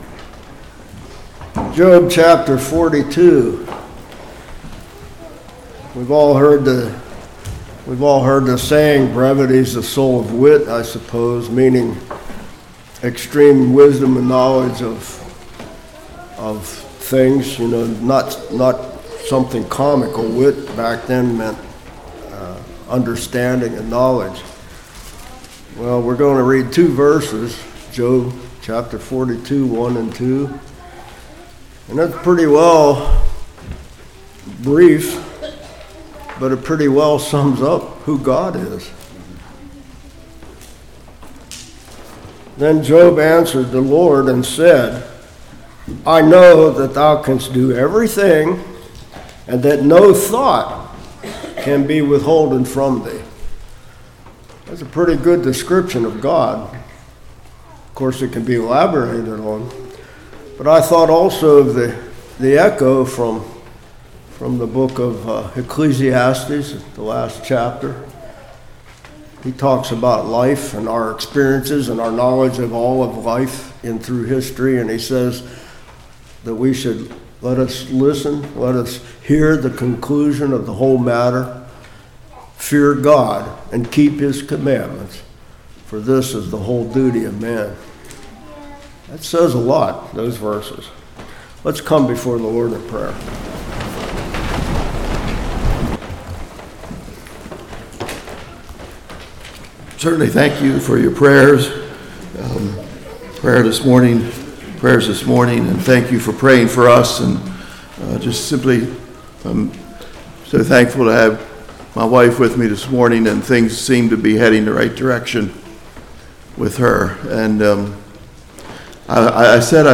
Job 42:1-2 Service Type: Morning Is Anything Too Hard For God?